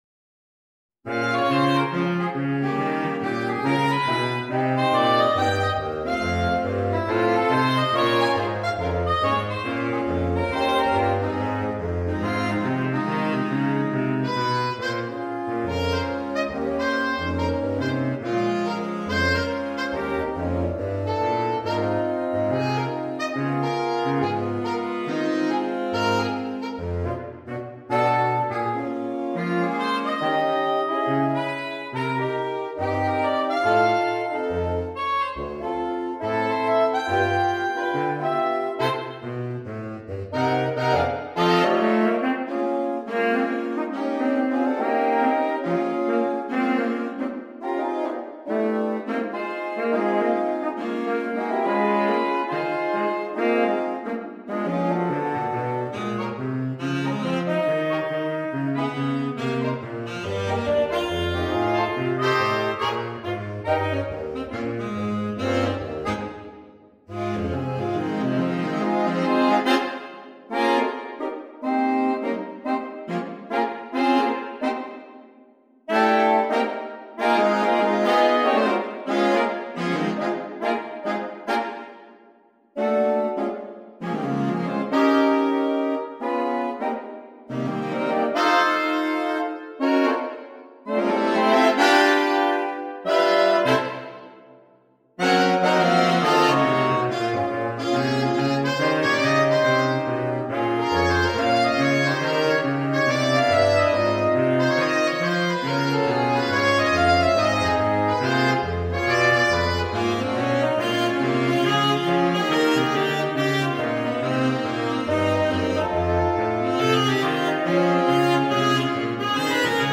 for Saxophone Quintet SATTB or AATTB
arranged for Saxophone Quintet